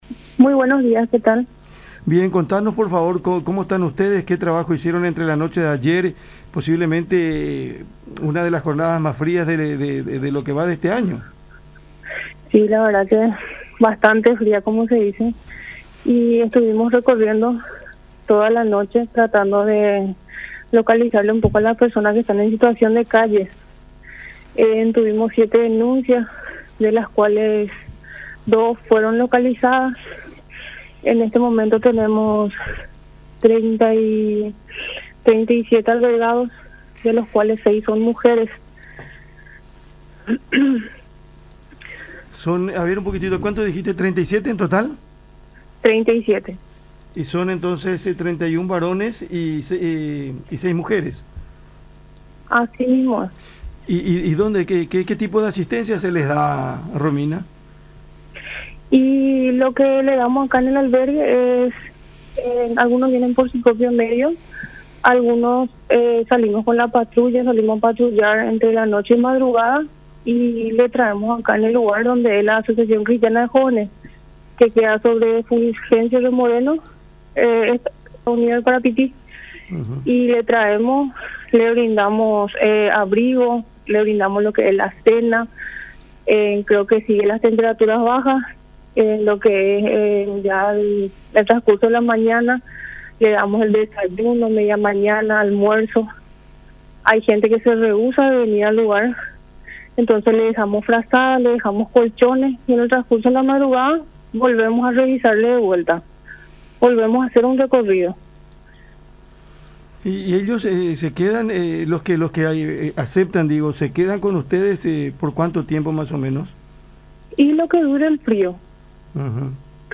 en diálogo con Cada Mañana por La Unión